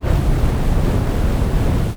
ZombieSkill_SFX
sfx_skill 11_2.wav